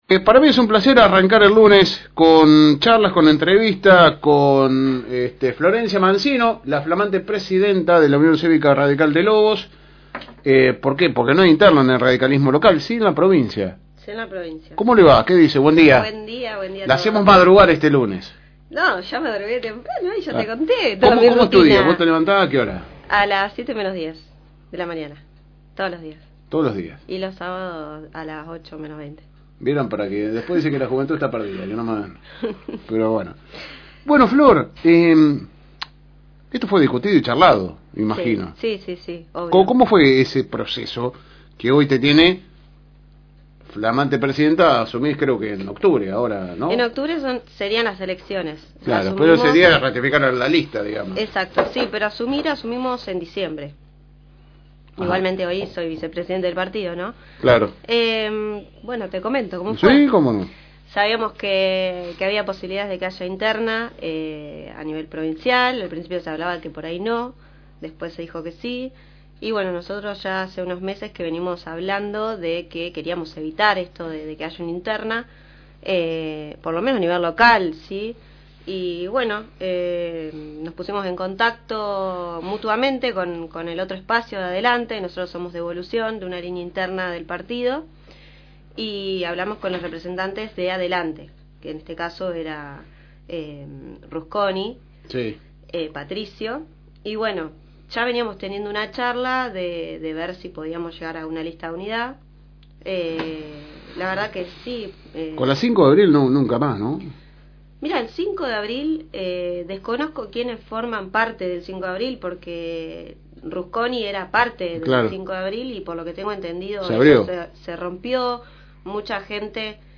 Tuvo una larga y extensa charla